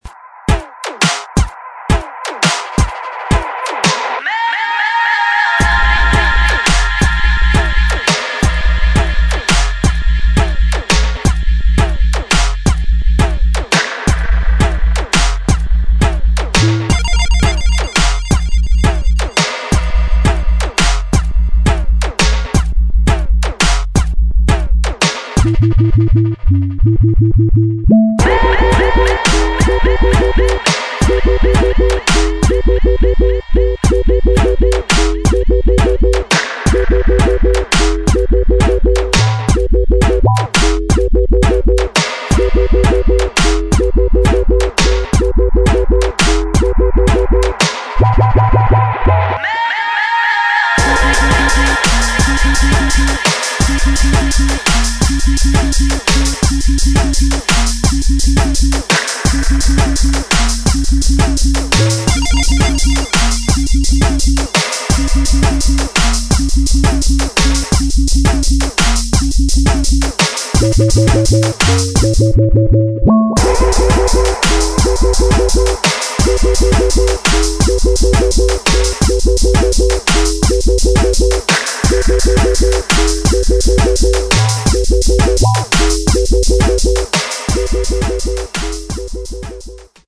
[ DRUM'N'BASS / JUNGLE / JUKE ]